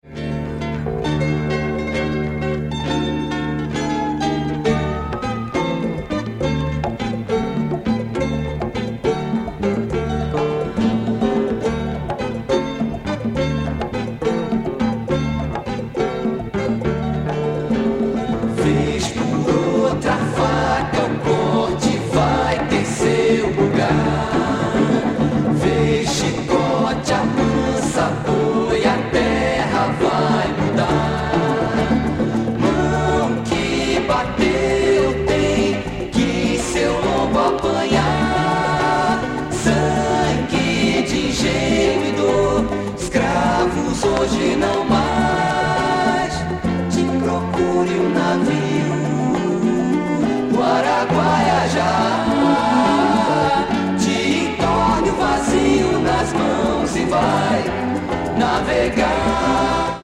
vocal group